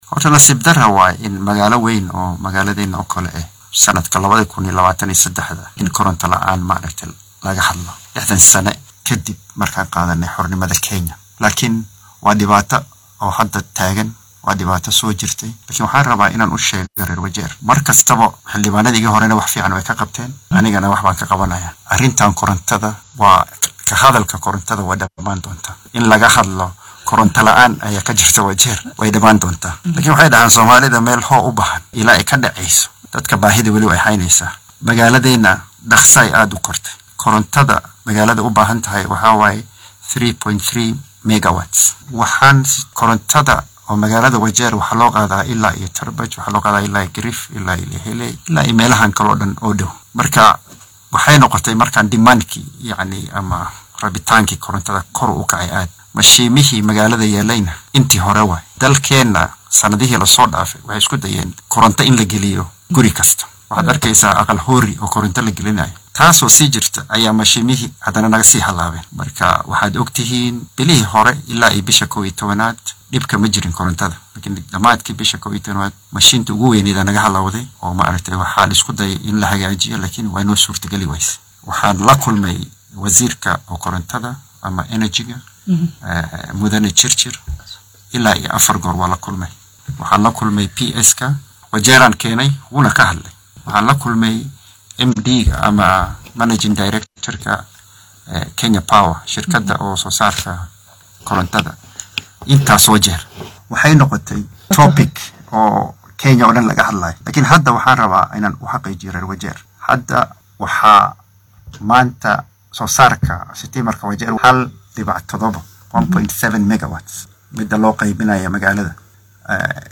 Mudanaha laga soo doortay deegaan baarlamaneedka bariga Wajeer Aadan Daauud oo wareysi gaar ah bixiyay ayaa sheegay inay jiraan dadaallo socdo oo xal waara loogu helaya cilladda dhanka korontada ee in muddo ah ka taagnayd ismaamulka Wajeer. Mudanaha ayaa xusay in bisha shanaad uu billaaban doono mashruuc lagu soo saaraya ilaa 8 megawatts oo koronto ah gudaha Wajeer.